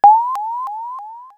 RetroGamesSoundFX / UFO / UFO02.wav
UFO02.wav